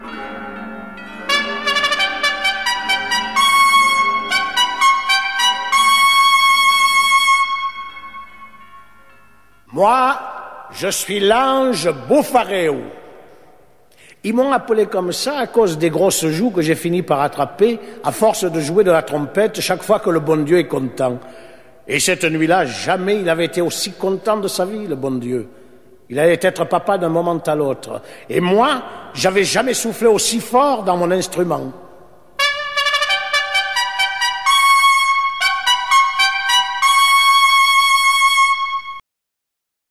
Une version audio supervisée par Marcel Pagnol a été produite en disque microsillon il y a … une soixantaine d’années avec des voix célèbres.
Nous ne résistons pas au plaisir de vous faire écouter les premières secondes de ce récit, donné par un ange.